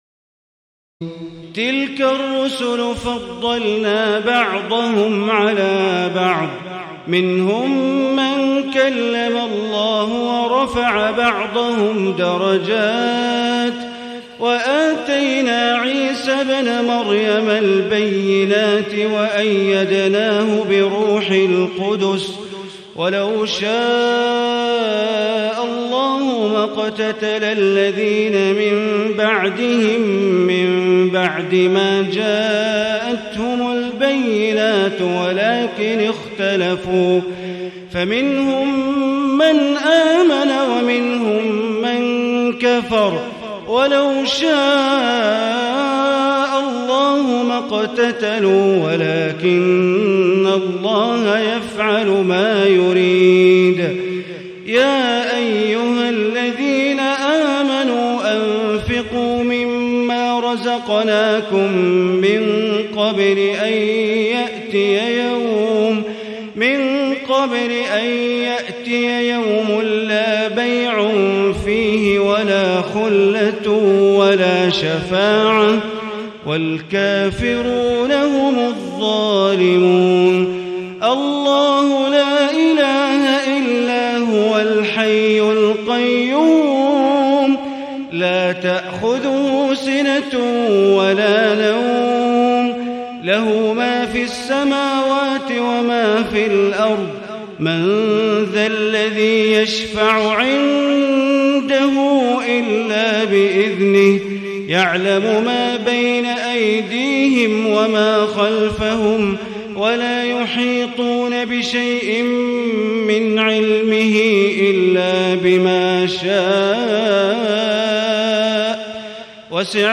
تهجد ليلة 23 رمضان 1437هـ من سورتي البقرة (253-286) و آل عمران (1-32) Tahajjud 23 st night Ramadan 1437H from Surah Al-Baqara and Aal-i-Imraan > تراويح الحرم المكي عام 1437 🕋 > التراويح - تلاوات الحرمين